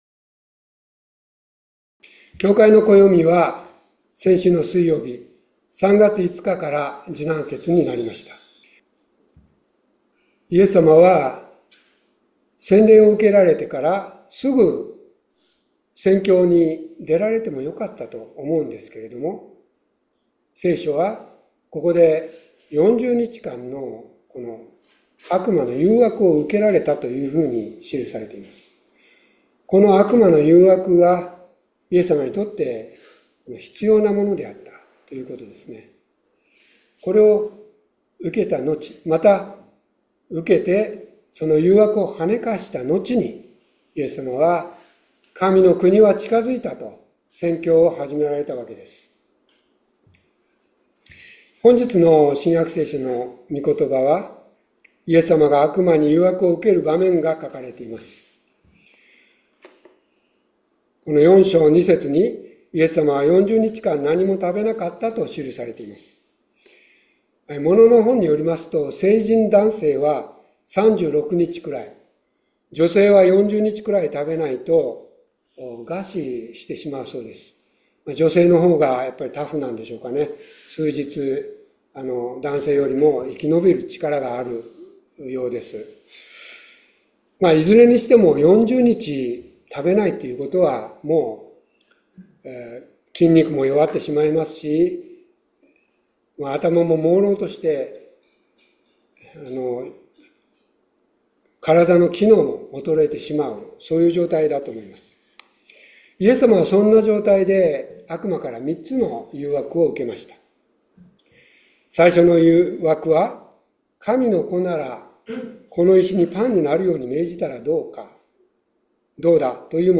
3月9日礼拝説教「悪魔の誘惑に勝つ」